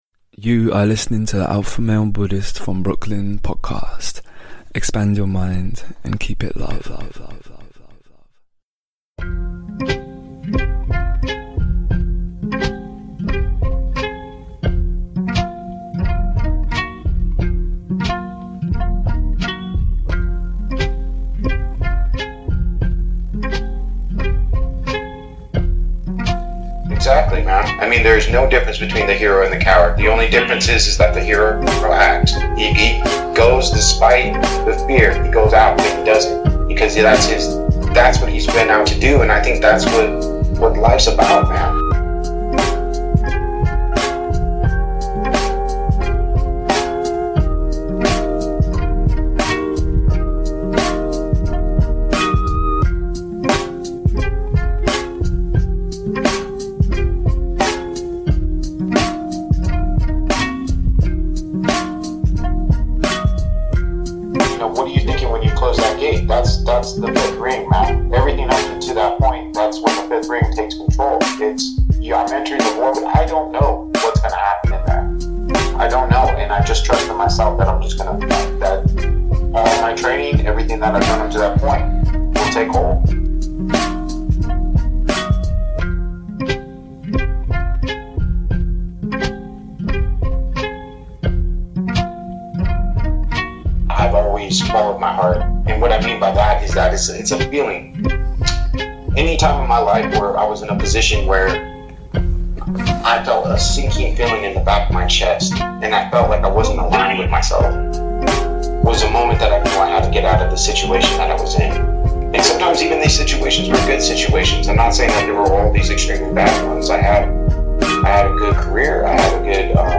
Interview Professional MMA Cage Fighter
Inside The Mind of a Warrior - 18 min of Beats Intro - Intv Starts at 19 min